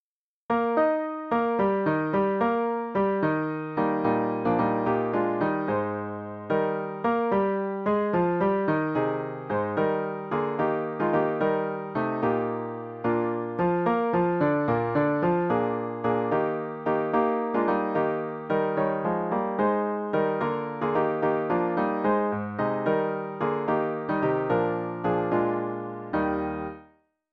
064-Sumar (Nú brosir ..) (6-8 taktur)